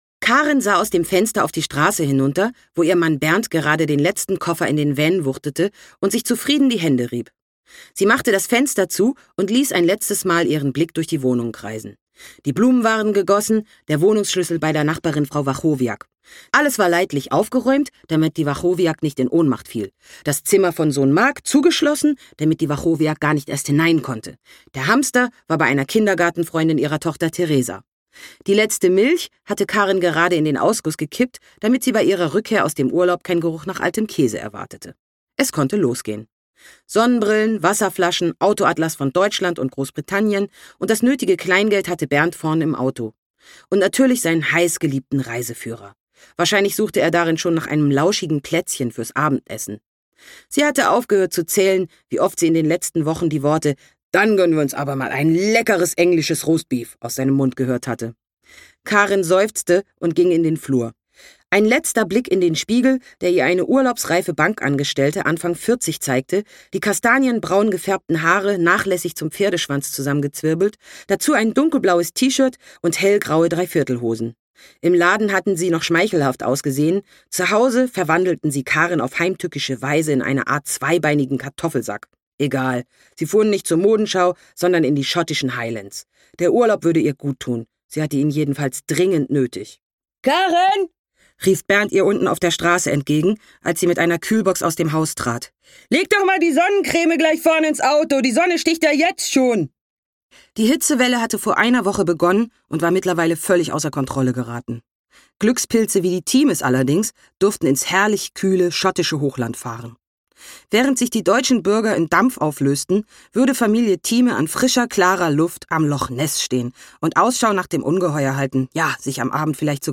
Tante Martha im Gepäck - Ulrike Herwig - Hörbuch